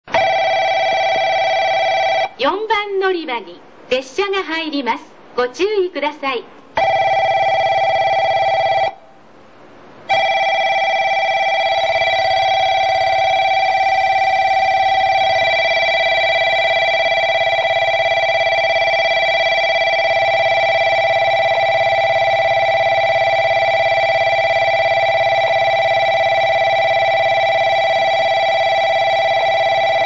スピーカー：ソノコラム
◆ 旧放送（2001年夏頃まで）
4 鹿児島本線 赤間・博多 方面
接近放送　(147KB/30秒) 九州標準 ＣＭＴ